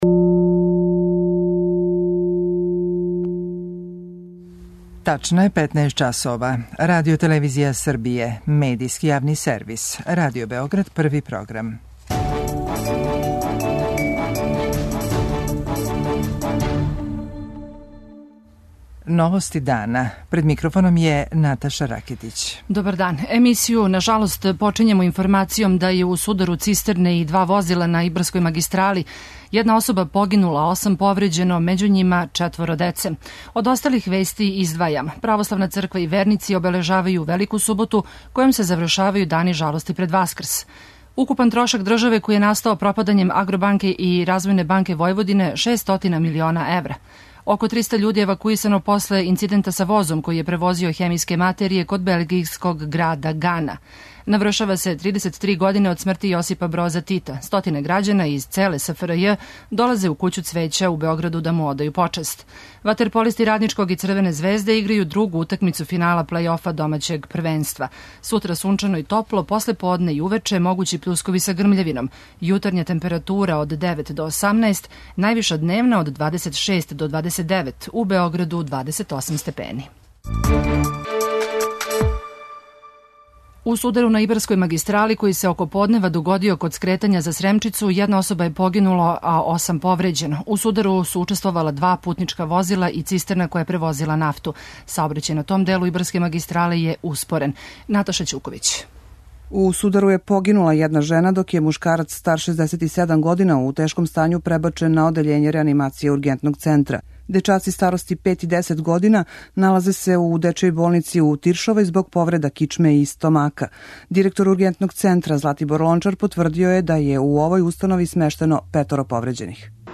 И репортер НОВОСТИ ДАНА посетио је Кућу цвећа и разговарао са посетиоцима.
преузми : 15.28 MB Новости дана Autor: Радио Београд 1 “Новости дана”, централна информативна емисија Првог програма Радио Београда емитује се од јесени 1958. године.